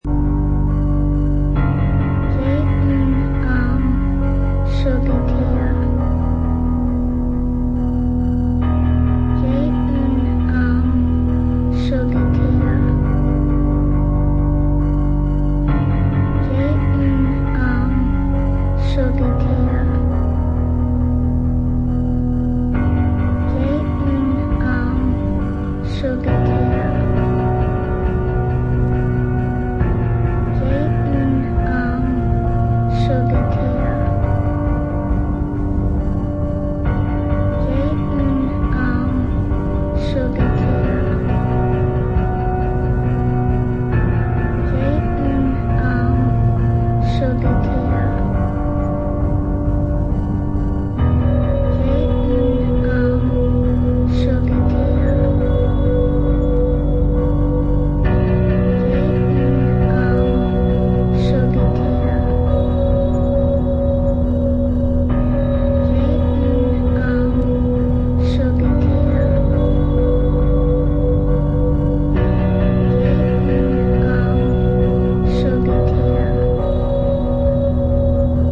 Cool Neofolk